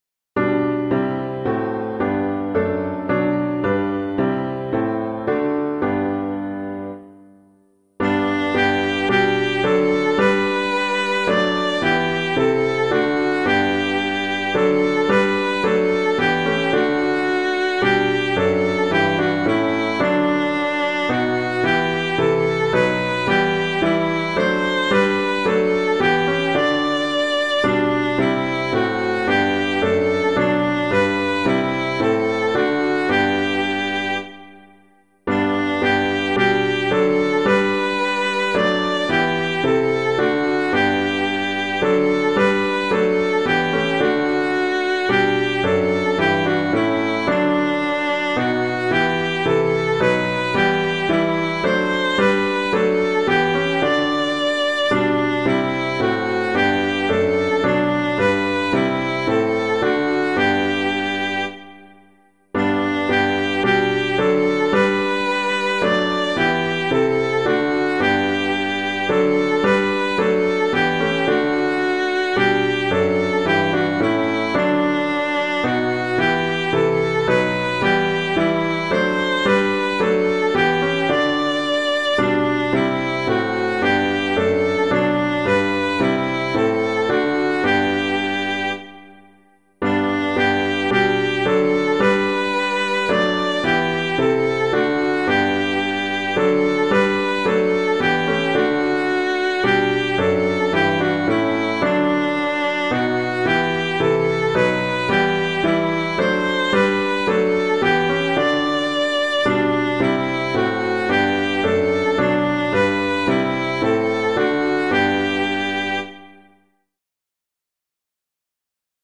piano
O Praise Ye the Lord Praise Him in the Height [Baker - HANOVER] - piano.mp3